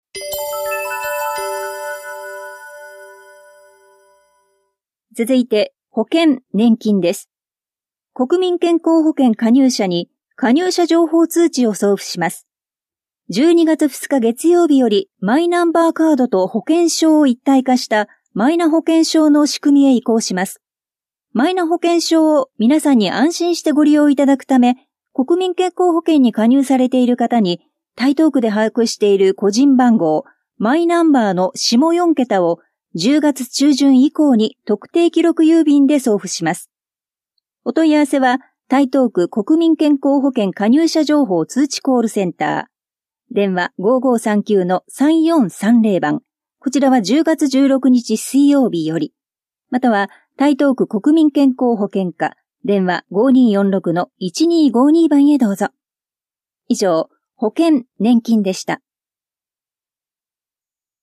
広報「たいとう」令和6年10月5日号の音声読み上げデータです。